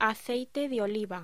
Locución: Aceite de oliva